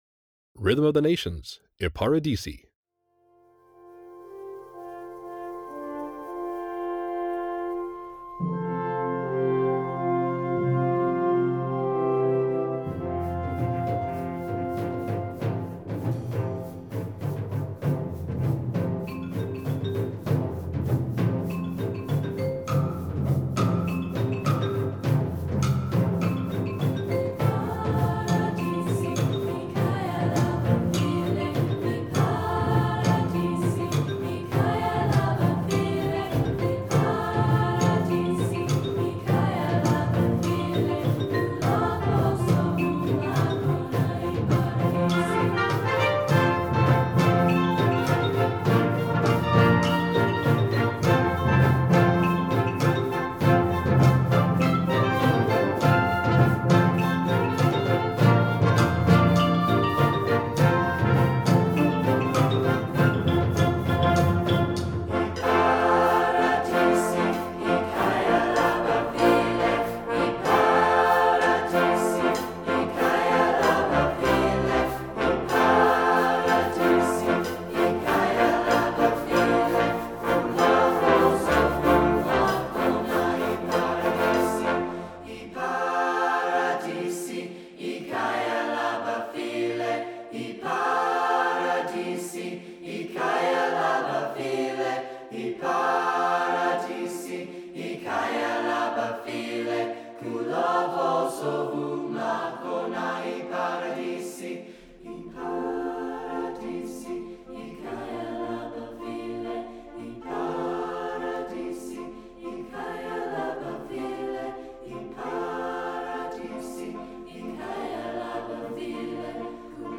Voicing: Concert Band